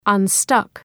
Προφορά
{ʌn’stʌk}